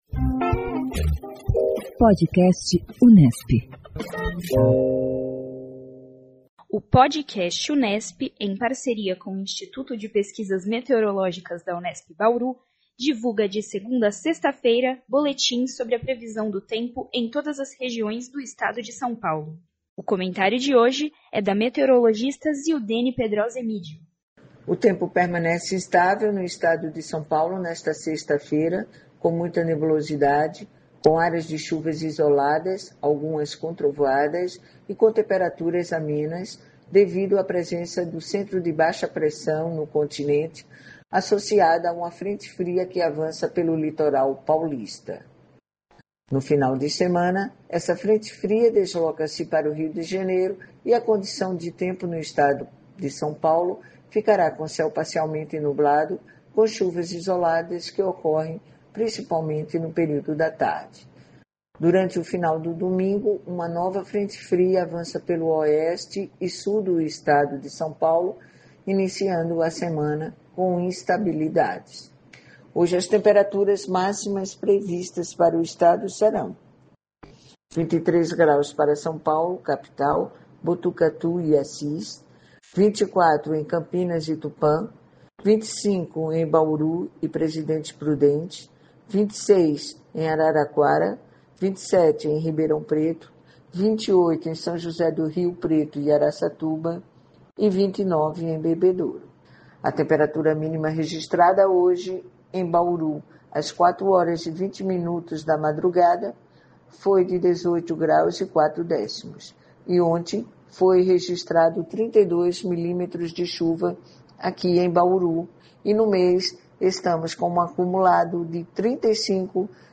O Podcast Unesp, em parceria com o Instituto de Pesquisas Meteorológicas da Unesp, divulga diariamente boletins sobre a previsão do tempo em todas as regiões do Estado de São Paulo.